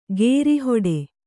♪ gēri hoḍe